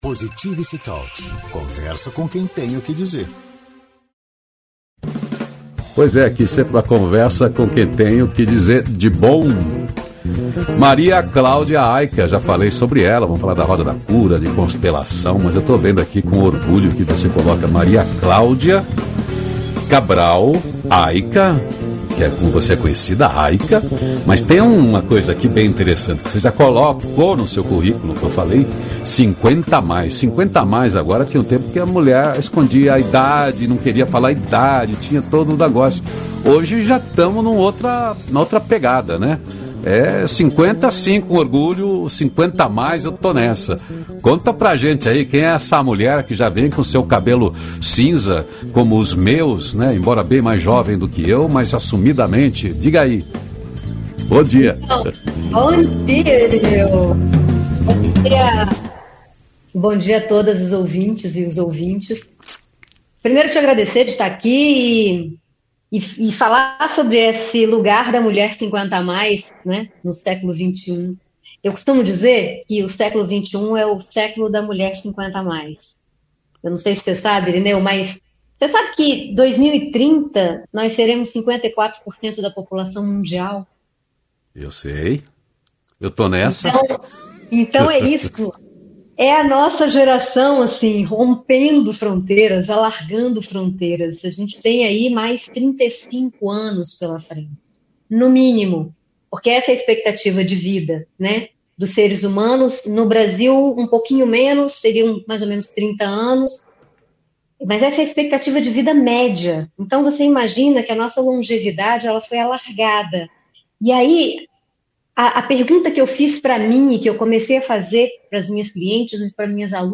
Conversa com quem tem o que dizer de bom.
O programa é um contraponto leve ao noticiário hard predominante na mídia tradicional de rádio e tv. O Feliz Dia Novo, é uma revista descontraída e inspiradora na linguagem de rádio (agora com distribuição via agregadores de podcast), com envolvimento e interatividade da audiência via redes sociais.